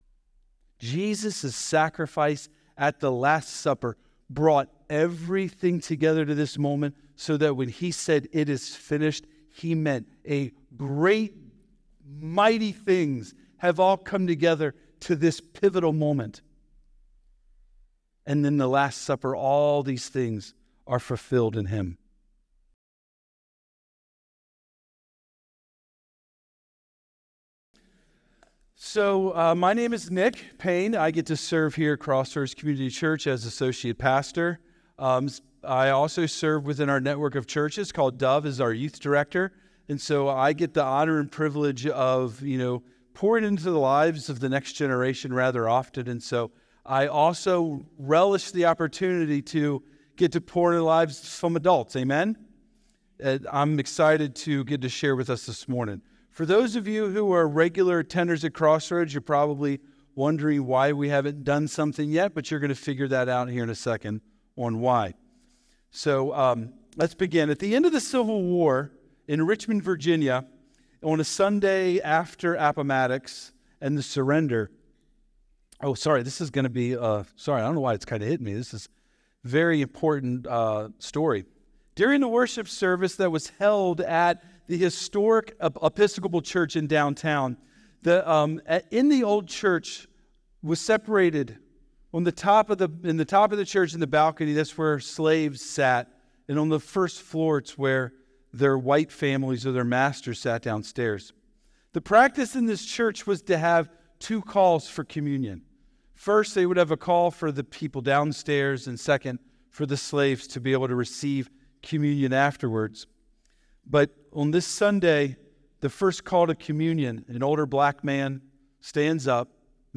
Sermon | Crossroads Community Church